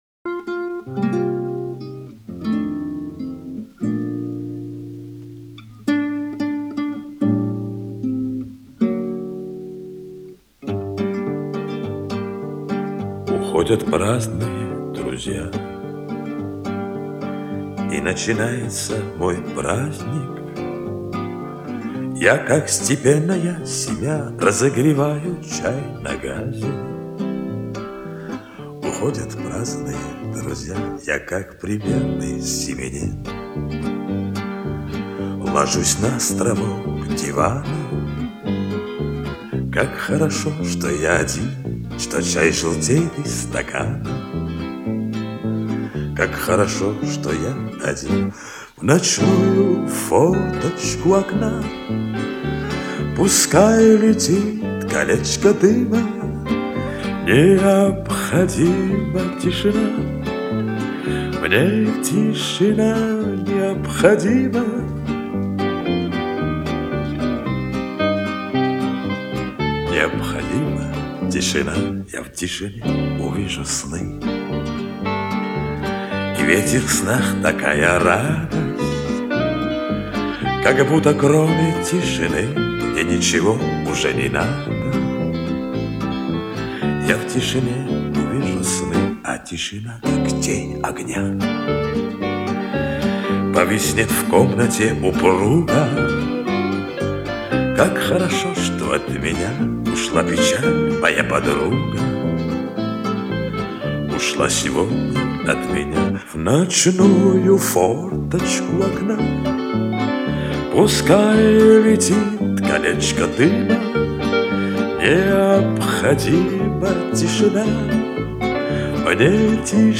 советская эстрада